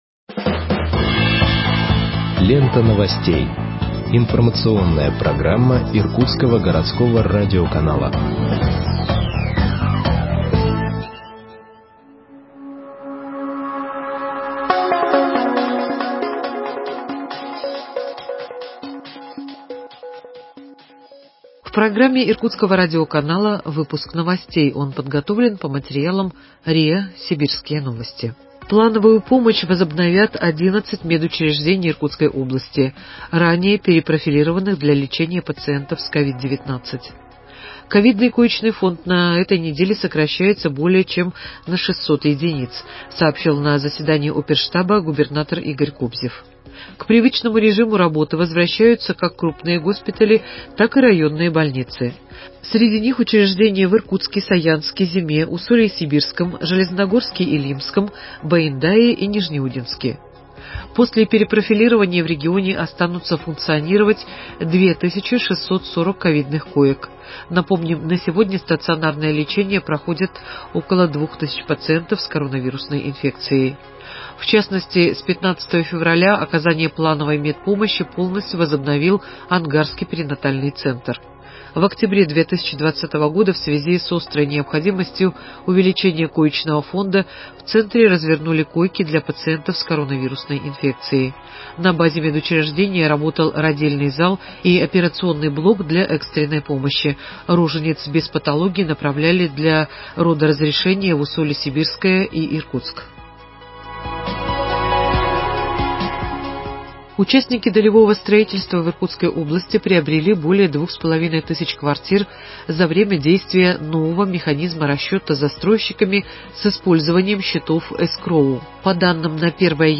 Выпуск новостей в подкастах газеты Иркутск от 18.02.2021 № 1